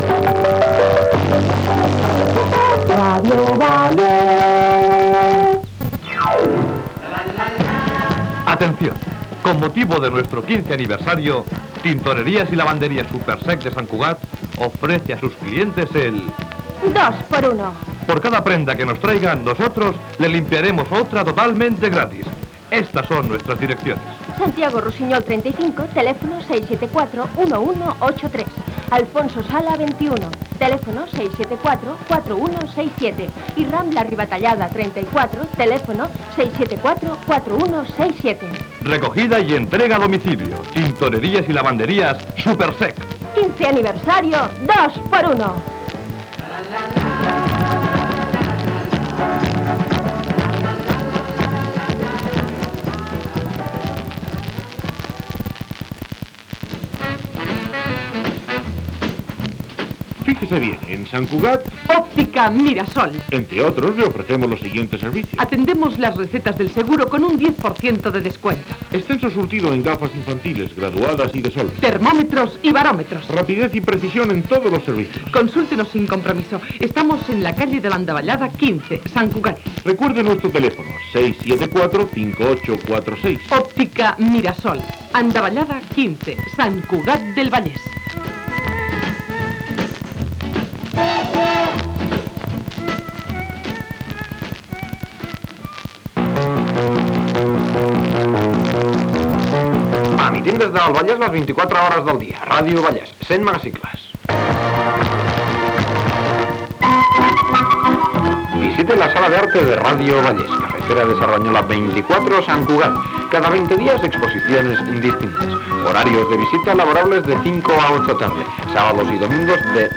Indicatiu i publicitat